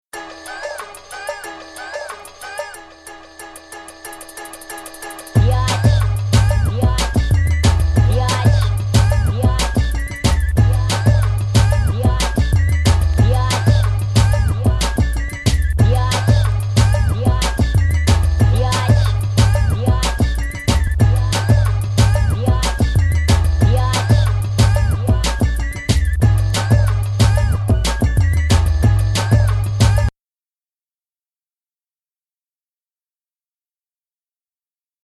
Straight up Detroit ghetto tracks
Electro Detroit